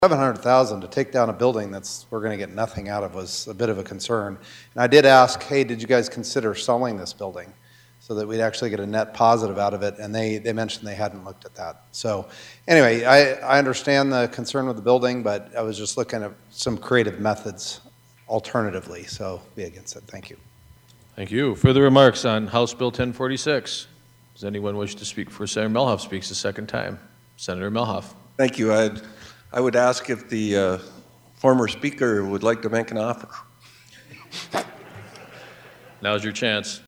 Senator John Carley opposed passage maintaining the building should be sold…